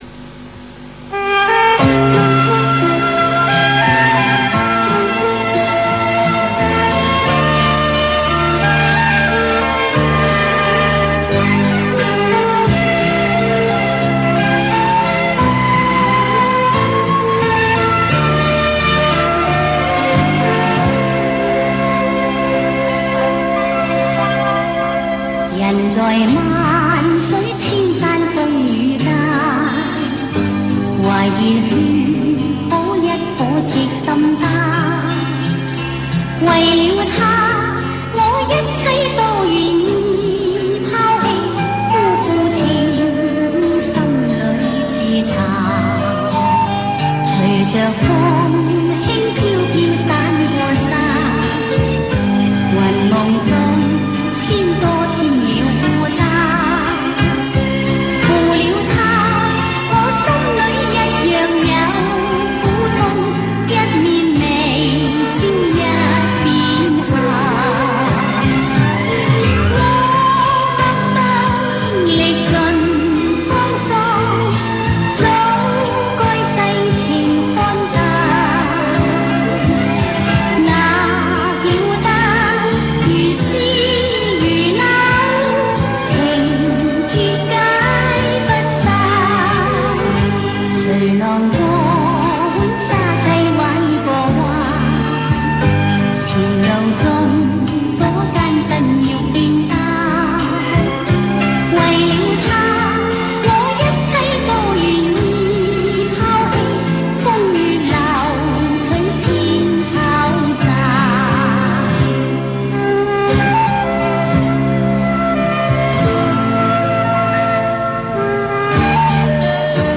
歌曲download:themesong